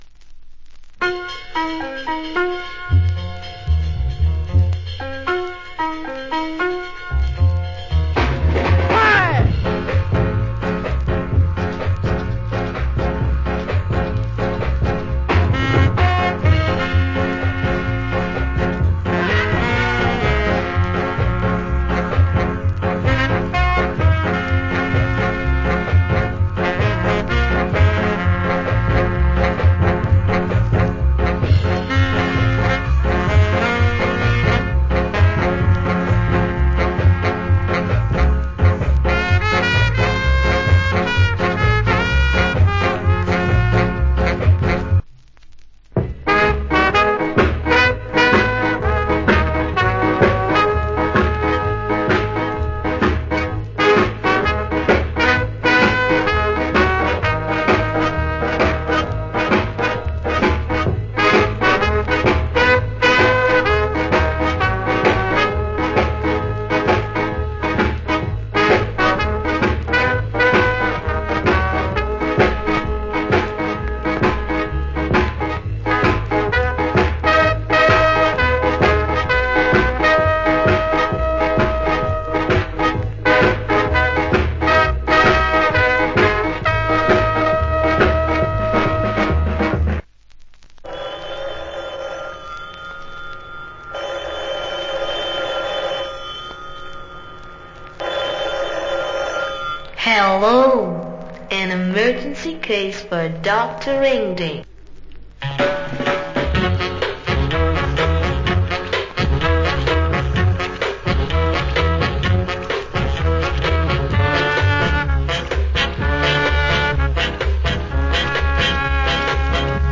Killer Ska & Rock Steady Intrumental.